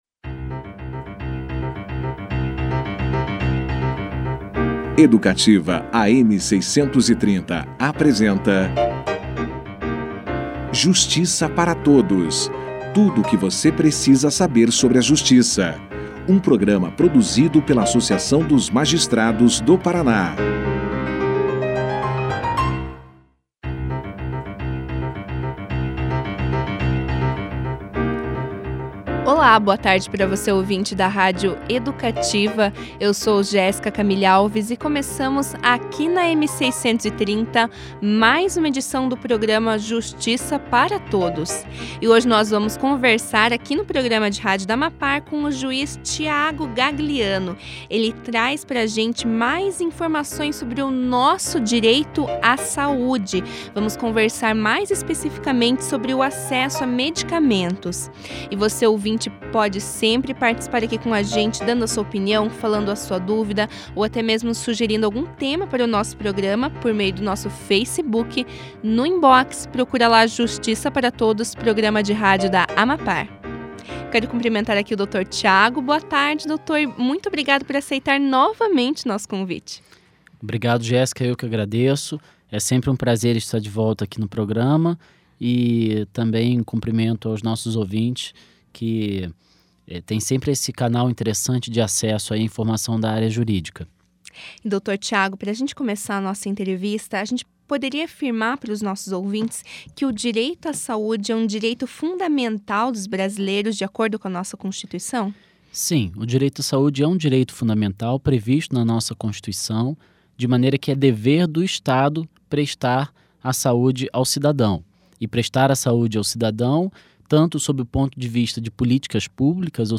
O Justiça Para Todos recebeu, nesta sexta-feira (02), o juiz Tiago Gagliano, que trouxe mais informações sobre o direito do cidadão à saúde e, mais especificamente, ao acesso a medicamentos.
O juiz também trouxe uma reflexão sobre os desafios dos magistrados na análise desse tipo de demanda. Confira aqui a entrevista na íntegra.